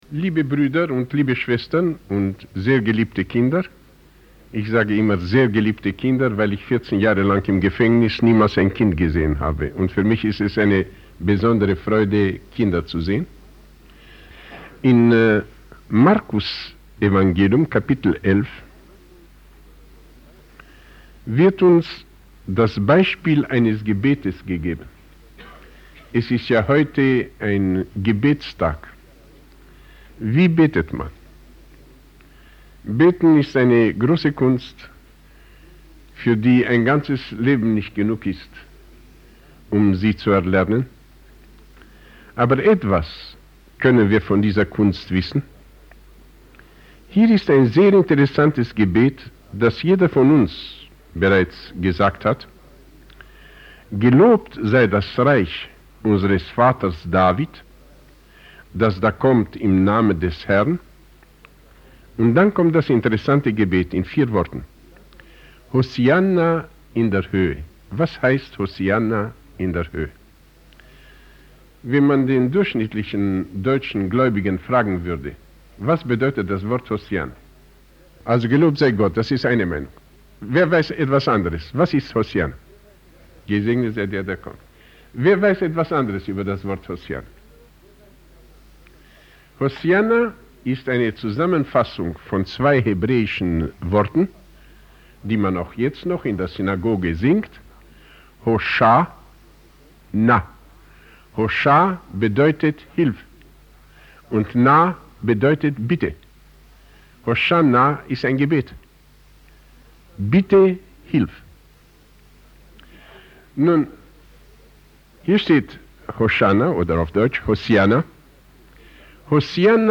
He calls for a collective approach to prayer, urging the congregation to embrace a spirit of unity and compassion, reflecting the heart of Jesus in their supplications.